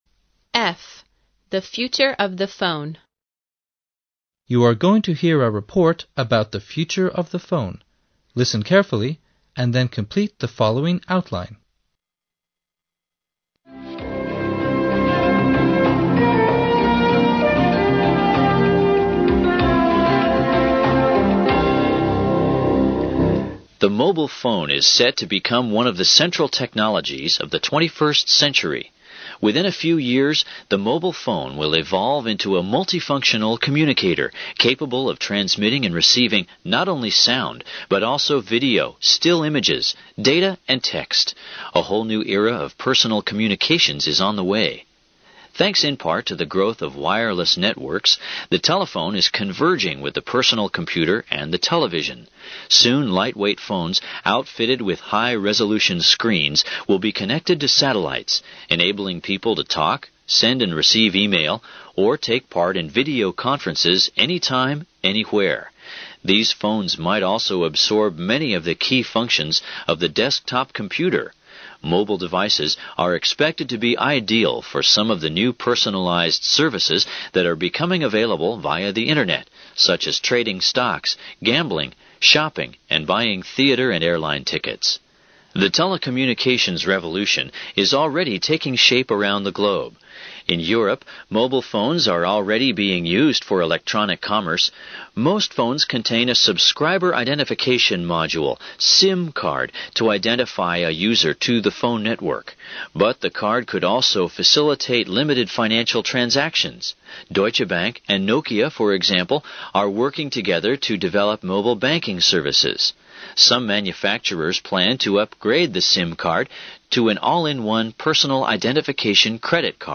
You're going to hear a report about the future of the phone, listen carefully, and then complete the following outline.